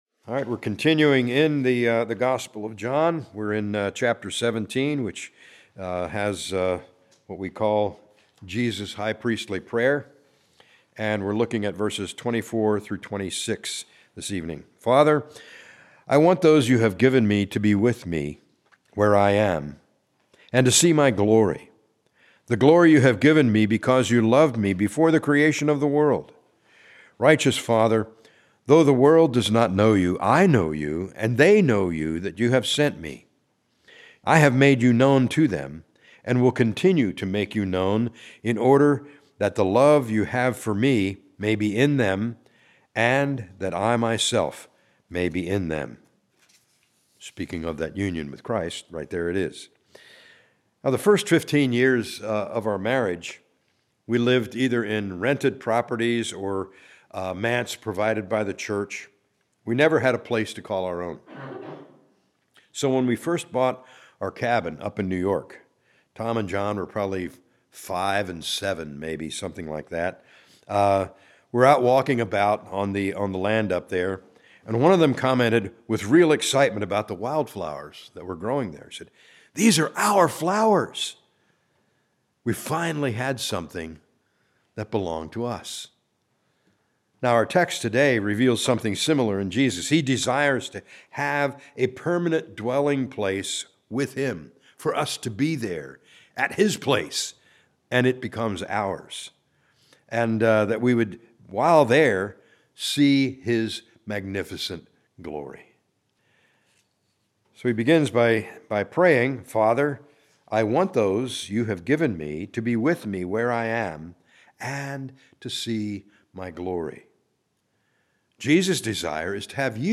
A message from the series "John."